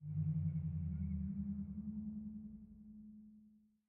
Minecraft Version Minecraft Version latest Latest Release | Latest Snapshot latest / assets / minecraft / sounds / ambient / underwater / additions / bass_whale1.ogg Compare With Compare With Latest Release | Latest Snapshot
bass_whale1.ogg